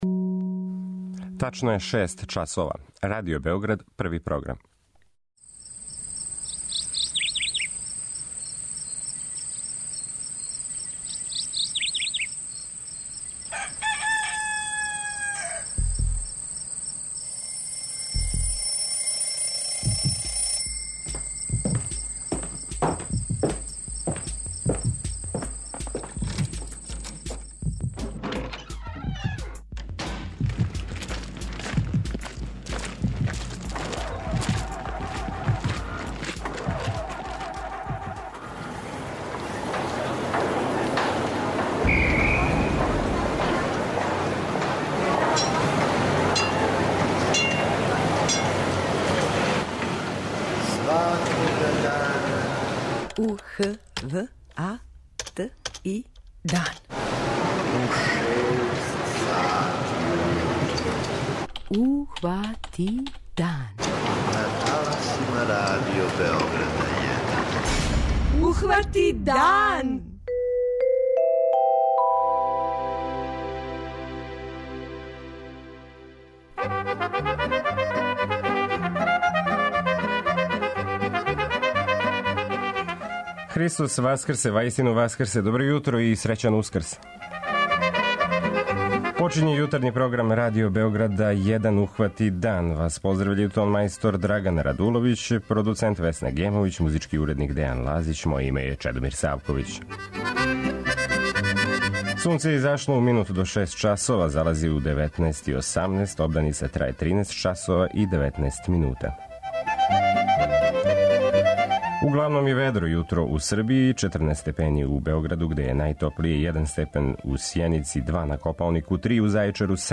преузми : 57.32 MB Ухвати дан Autor: Група аутора Јутарњи програм Радио Београда 1!